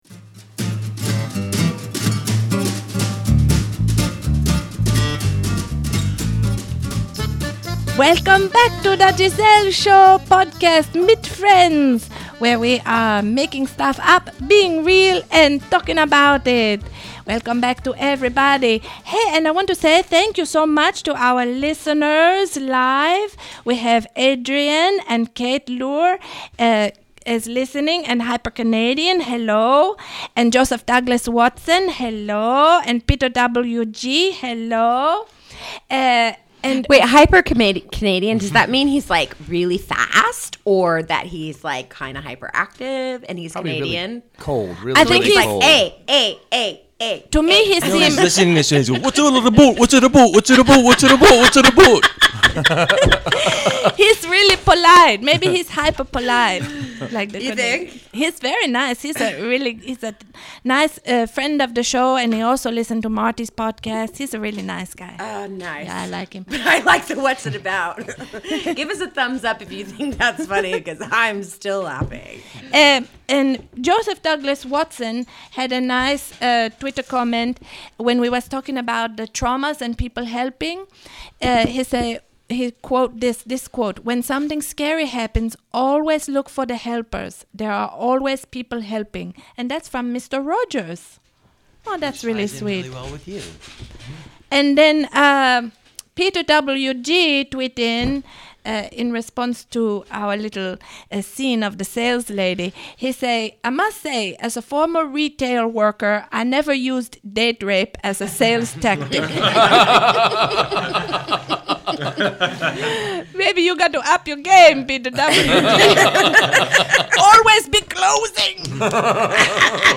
Photos from the Live Recording: